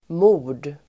Uttal: [mo:r_d]